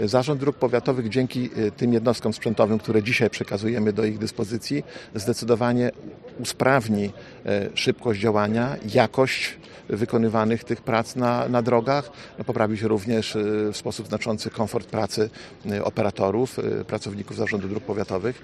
O poprawie pracy na drogach mówi Lech Marek Szabłowski, Starosta łomżyński: